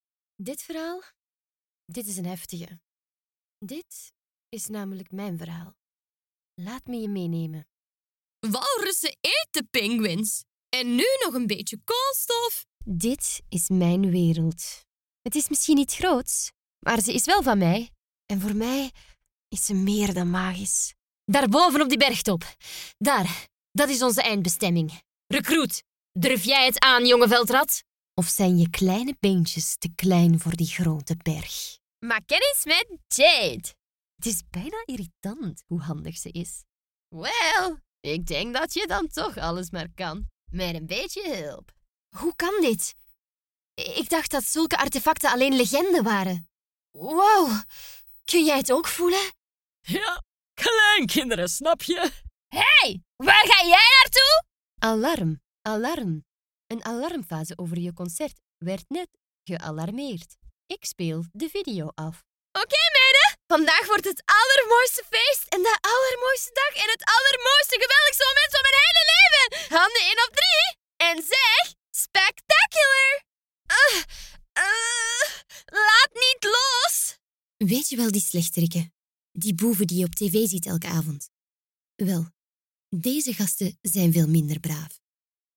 Commercial, Young, Natural, Versatile, Friendly